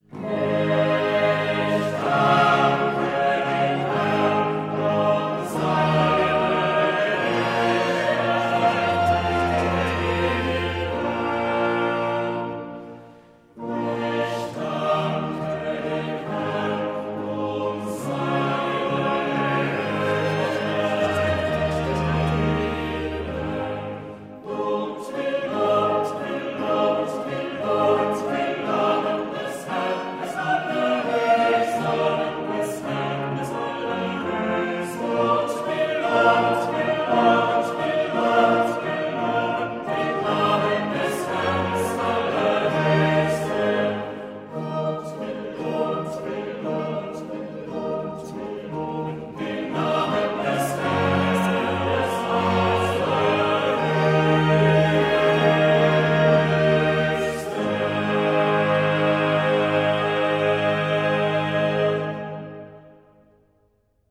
Instrumentalisten